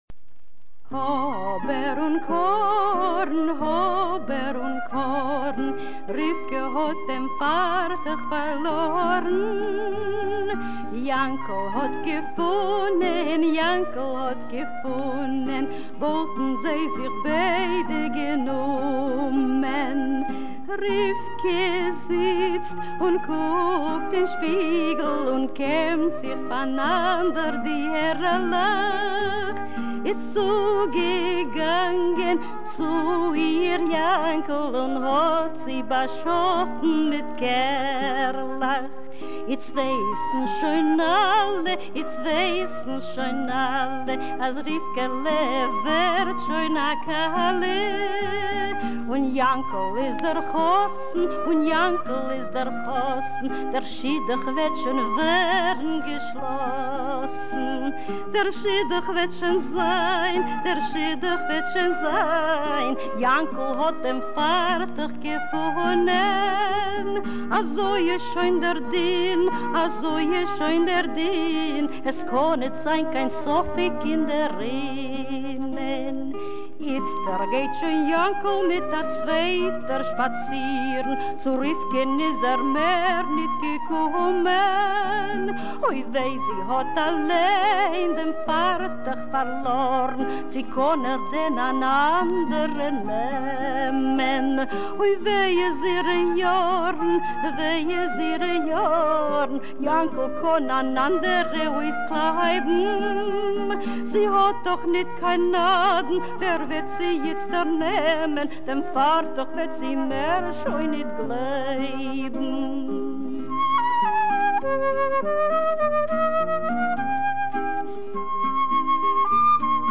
Our Yiddish folk song is Hober un Korn (Aka Dem fartekh farlorn) - In Oats, in Rye - also about love and marriage, gone a slightly unusual way. Folklorist Ruth Rubin specifies its genre as Satirical Marriage Song.
Ruth Rubin's recording: